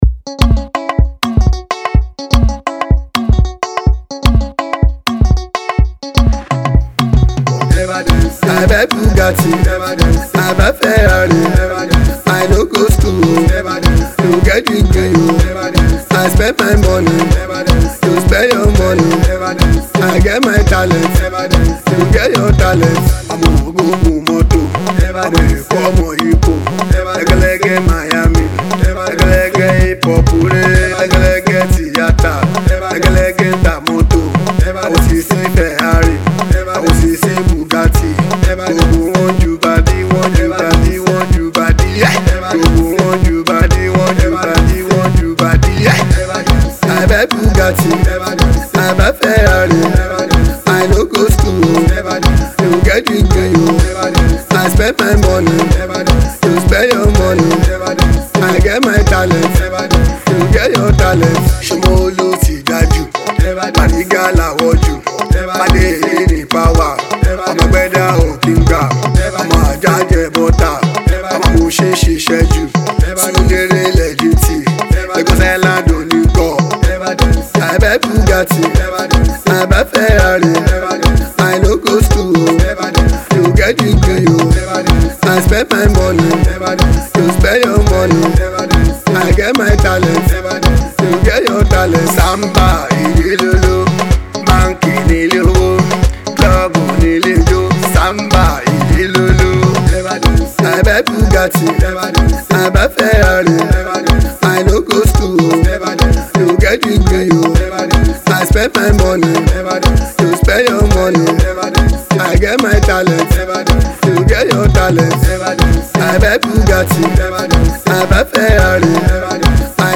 Alternative PopAudioNeo-Fuji
has this contemporary Fuji vibe to it